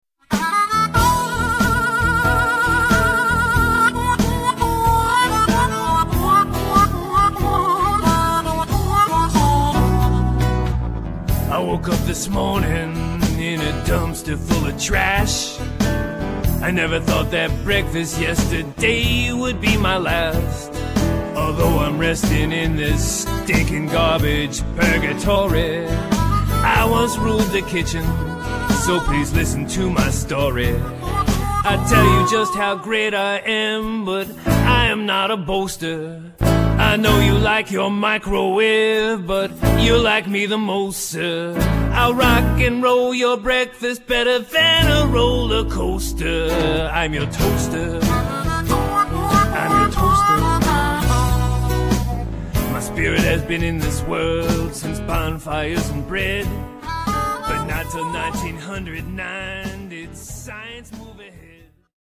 --funny kids' music